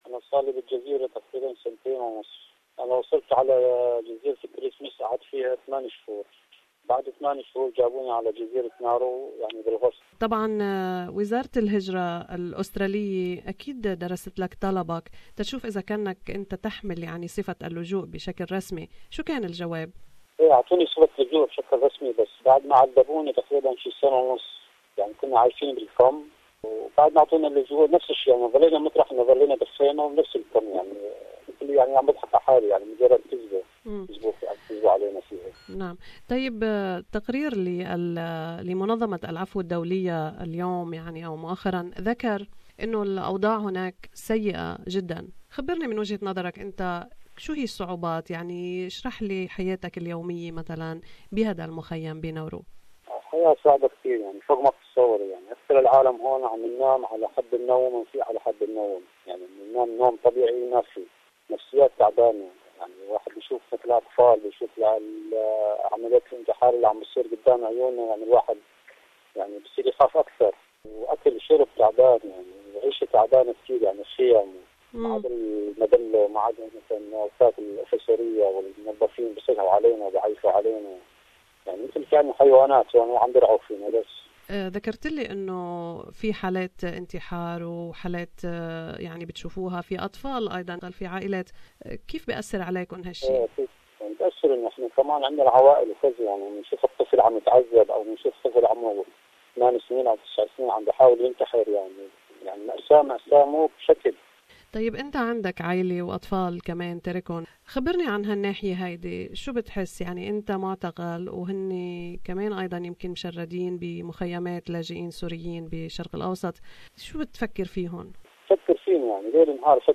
لقاء مع معتقل في جزيرة نارو التي وصفتها منظمة العفو الدولية بجزيرة اليأس